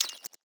ChargeSelectStop.wav